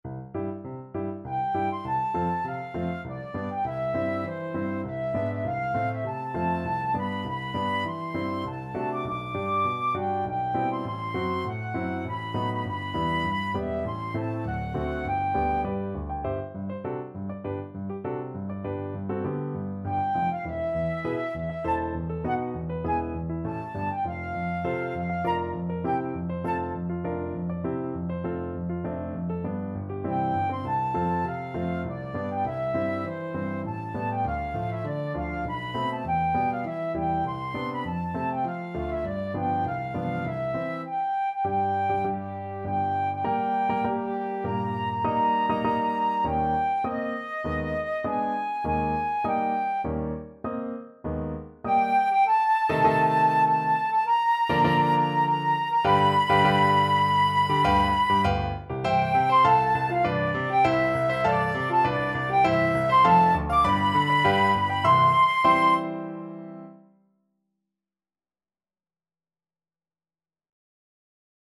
Flute
C major (Sounding Pitch) (View more C major Music for Flute )
~ = 100 Tempo di Menuetto
3/4 (View more 3/4 Music)
Classical (View more Classical Flute Music)